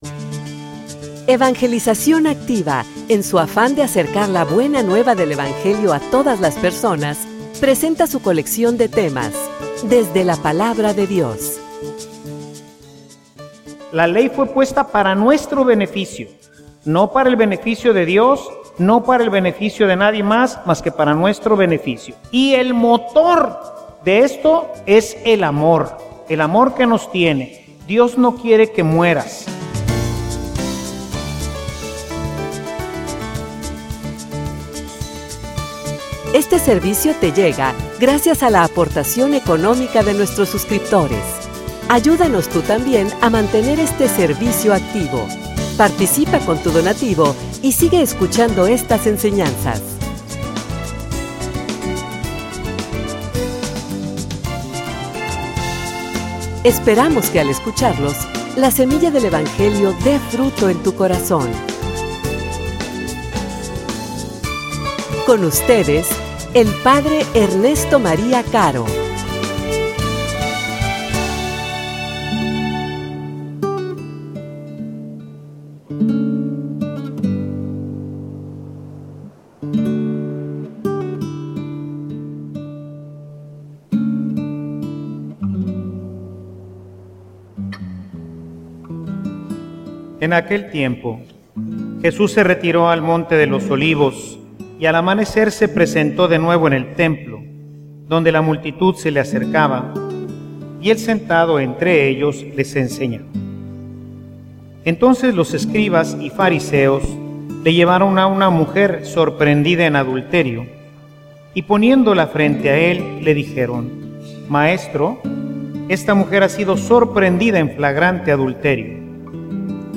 homilia_No_manipules_la_ley.mp3